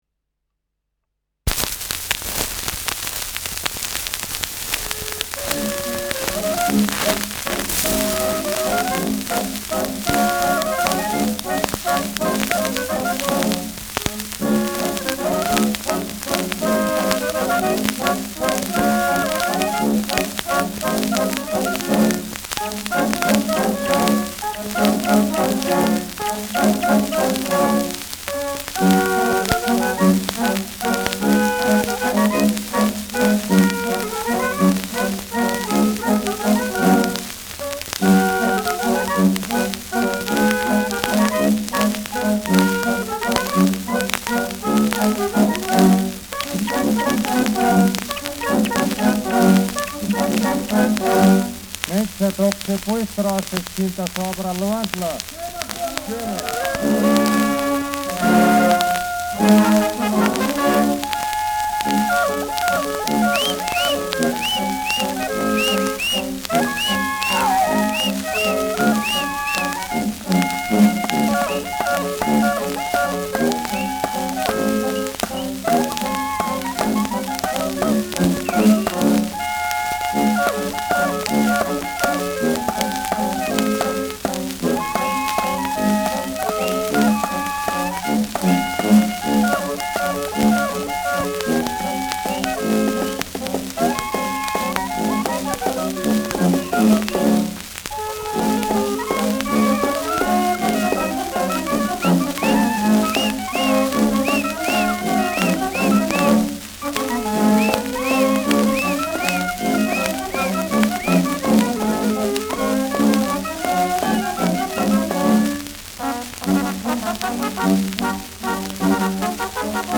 Schellackplatte
präsentes Rauschen : Knistern
[unbekanntes Ensemble] (Interpretation)
Mit nachgeahmtem Vogelzwitschern.